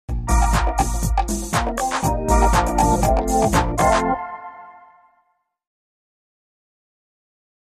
Music Logo; Short Dance Groove Beat, With A Happy Feel.